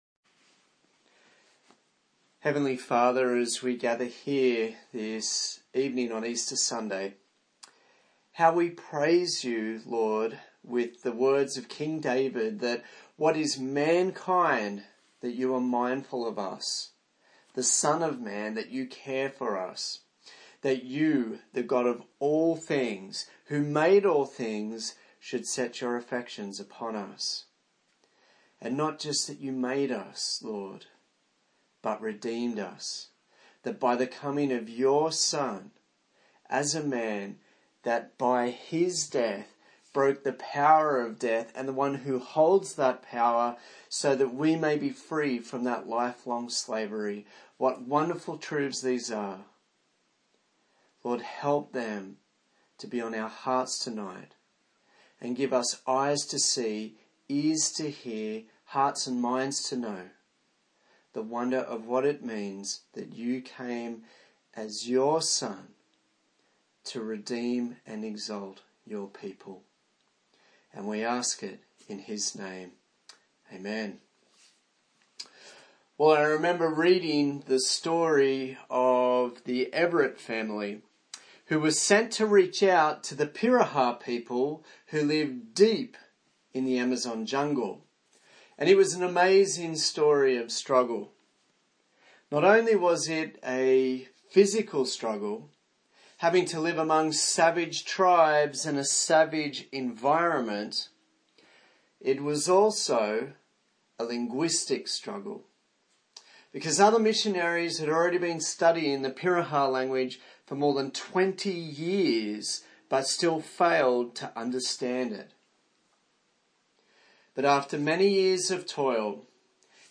A sermon on the book of Hebrews